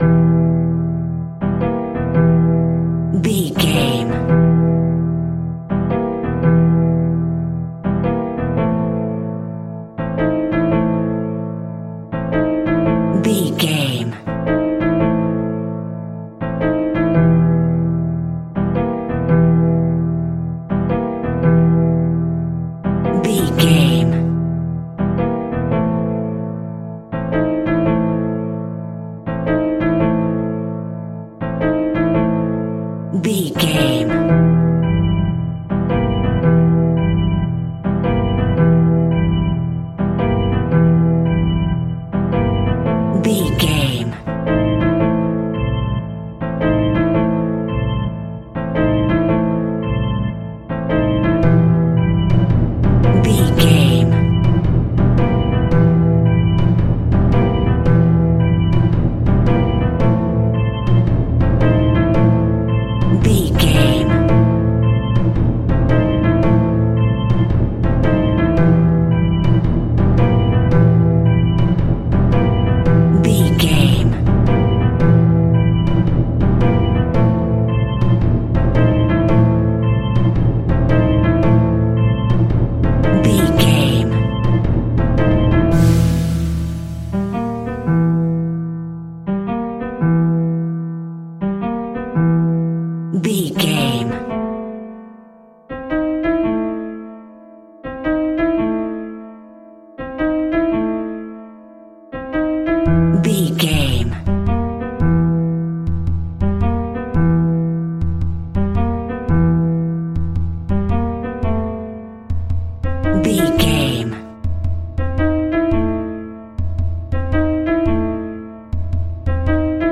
Aeolian/Minor
scary
ominous
eerie
piano
strings
electric piano
organ
percussion
drums
spooky
horror music